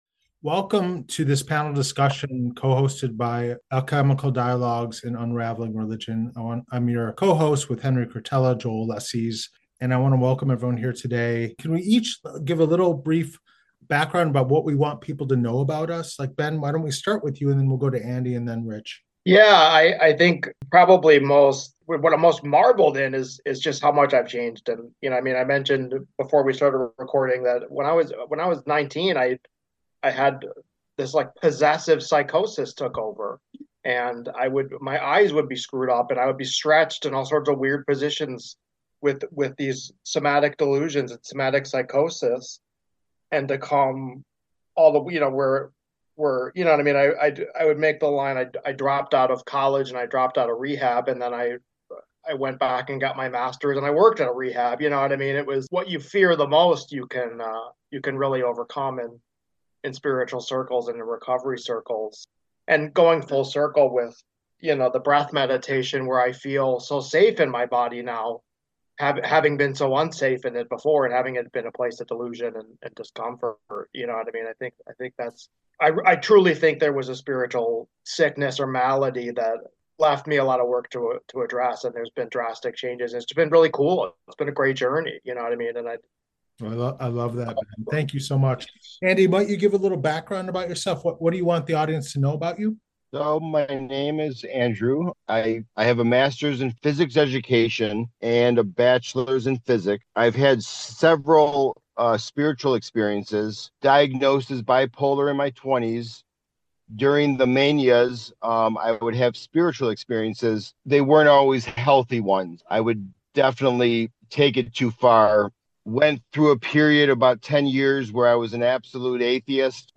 Part 1 In the continuation of the cross-collabaortion of Alchemical Dialogues, Unraveling Religion, and The Labyrinth, we are posting this Panel Discussion.